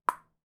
ping_pong.wav